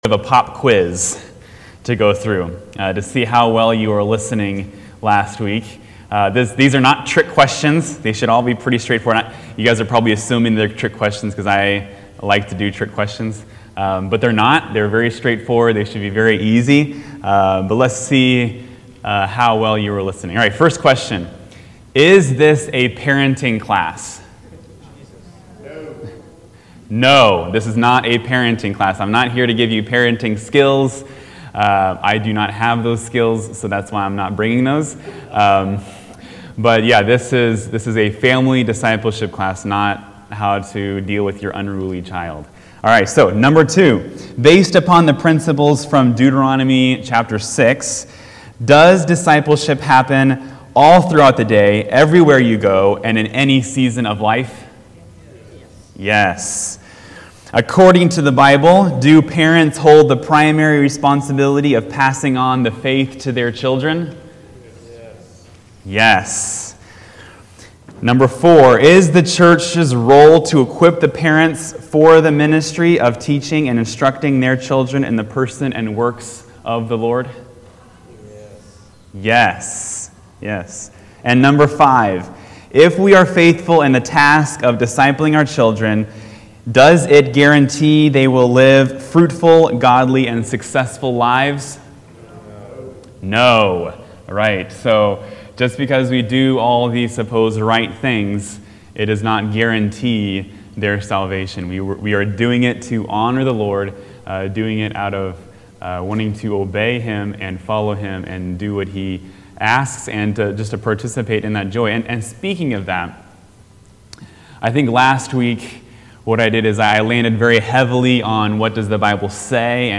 Adult Sunday School class